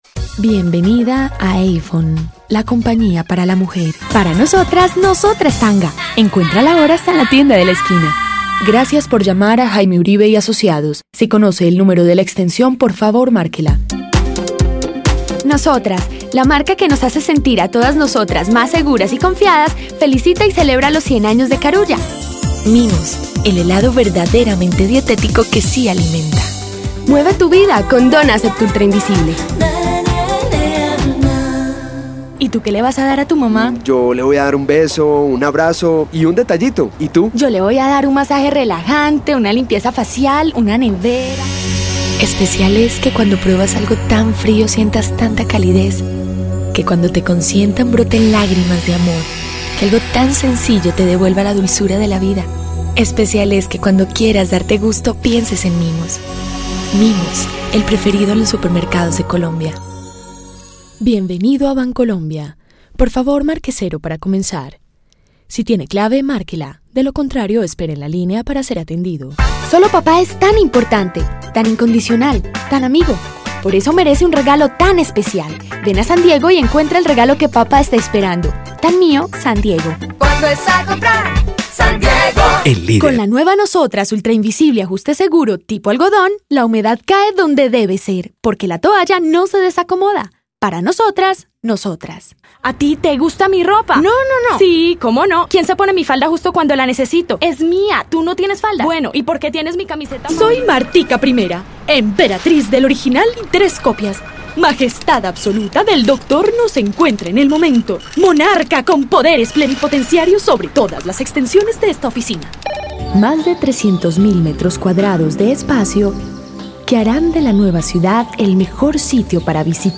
Si necesitas acento neutro latinoamericano, castellano o colombiano, esa es mi voz.
Sprechprobe: Werbung (Muttersprache):